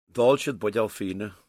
Intonational differences between Irish dialects
Irish intonation
Gaoth Dobhair, Co. Donegal